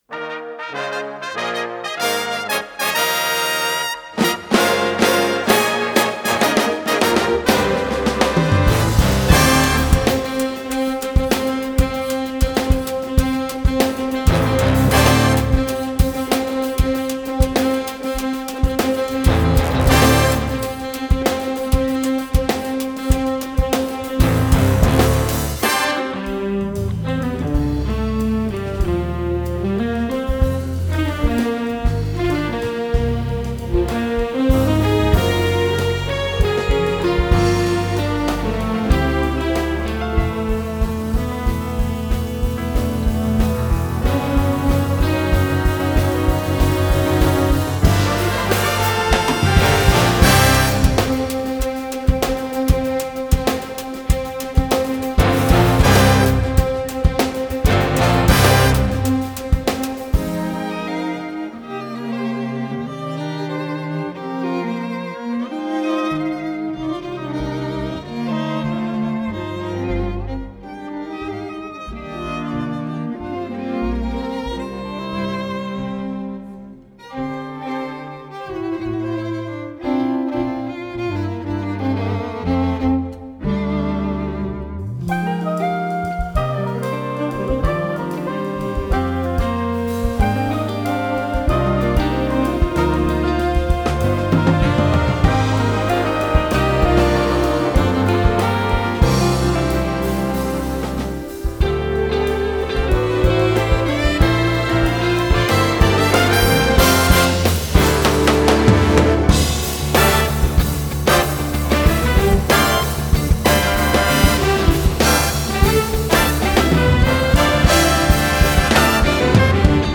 alto sax
guitar
En niet te vergeten een heroïsche gitaarsolo.”